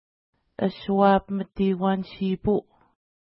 Pronunciation: əswa:pməti:hkwa:n-ʃi:pu: